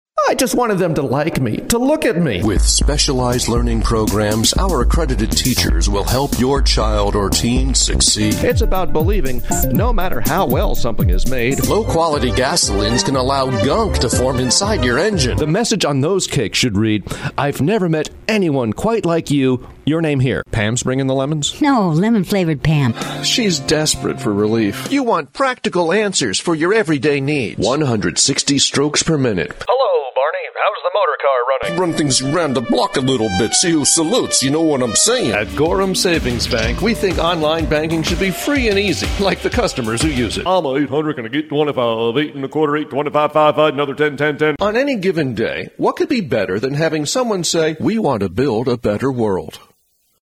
Voiceover Demo